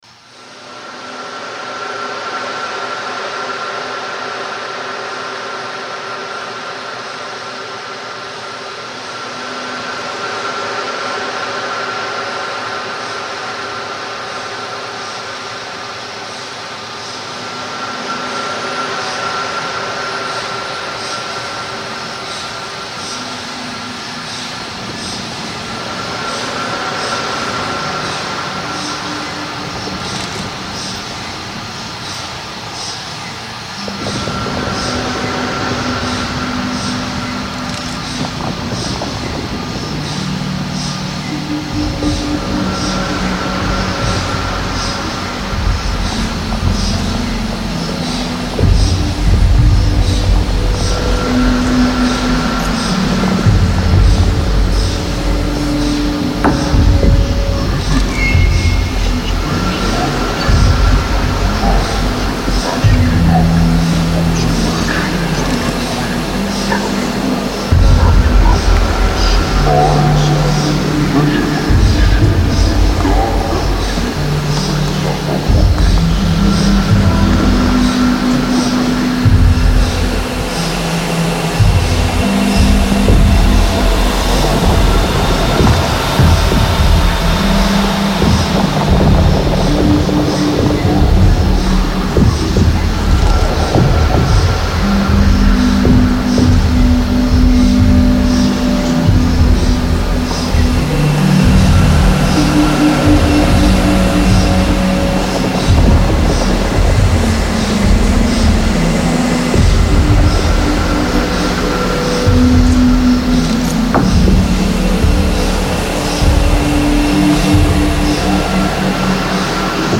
Everything heard within this piece is created from processed audio captured within CERN.
I wanted to create a dreamlike version of the type of sounds I imagined CERN might produce if you could hold a contact mic against the ground within the complex - capturing all the sounds of the various experiments and activities going on (some of CERN's experiments are deep underground).
Using midi randomisation across the various contact mic recordings, I created a morphing array of the various knocks and clunks produced from CERN machinery. The addition of a grain delay effect on some of this audio feels like it might be a slowed down recording of the numerous particle collisions performed within CERN experiments. In the background, meanwhile, the machines at the antimatter factory continue to chug away. Processed sections of this audio provide the airy pad like sounds, whilst pitched down audio from the Universe of Particles exhibition provide the melodies and voice.